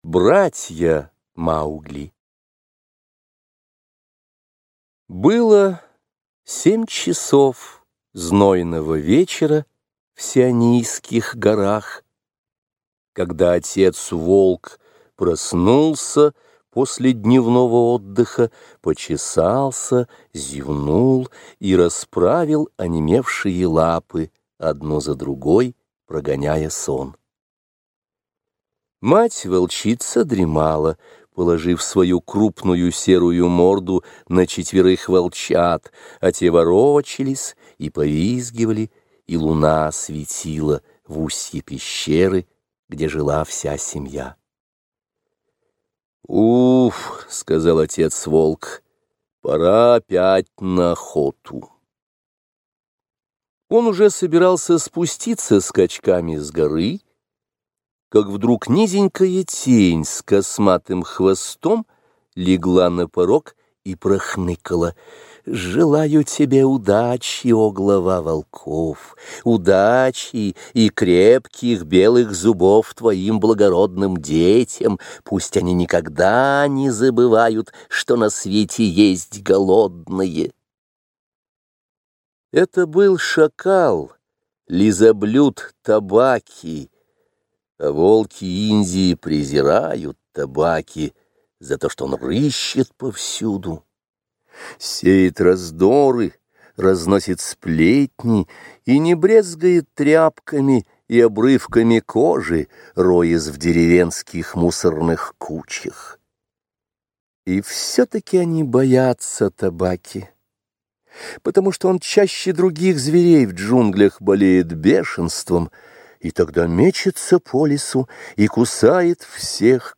Слушайте Первая книга джунглей. Братья Маугли - аудиосказку Киплинга.